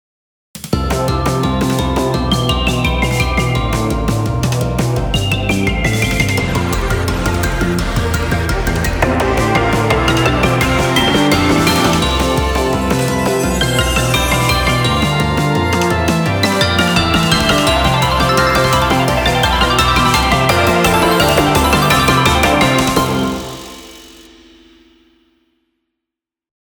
どんちゃんわいわい、無法地帯。
inst